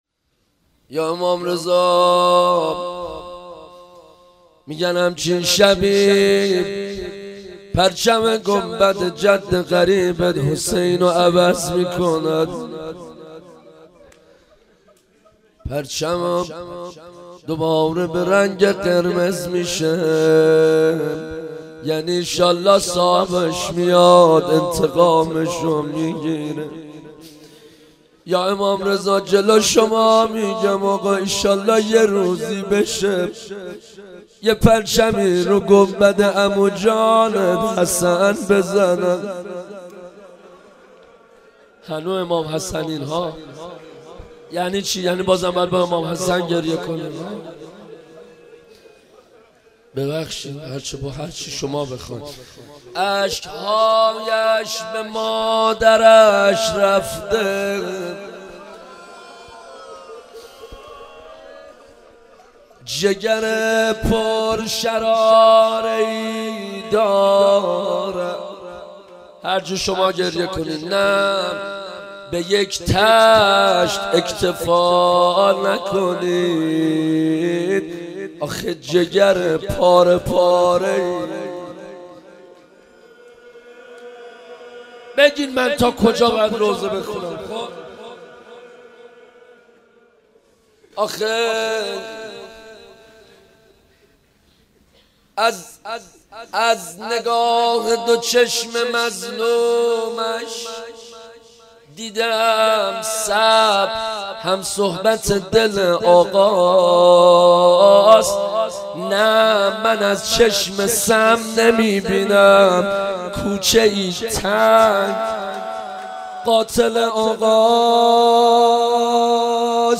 روضه امام رضا